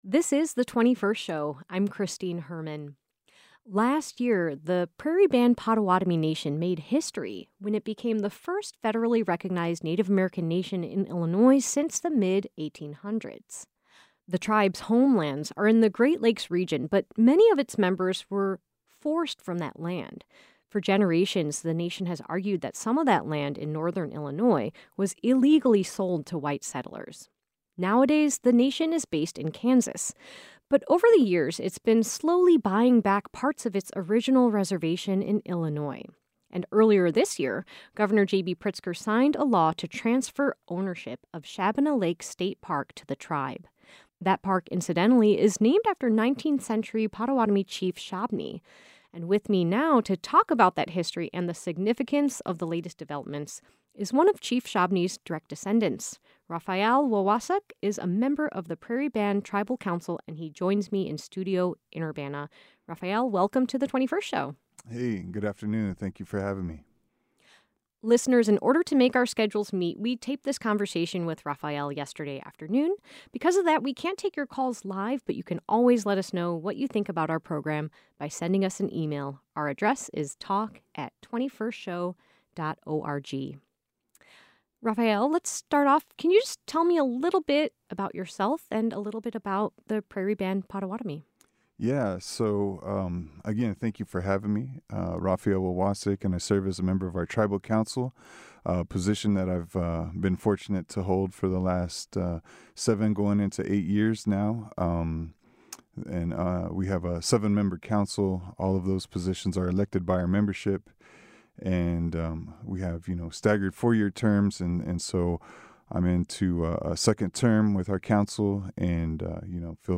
Earlier this year, Governor JB Pritzker signed a law to transfer ownership of Shabbona Lake State Park to the Prairie Band Potawatomi Nation. A member of the Prairie Band tribal council discusses the history of the tribe, its connection to Illinois and what this move means for the Prairie Band Potawatomi Nation.